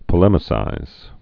(pə-lĕmĭ-sīz)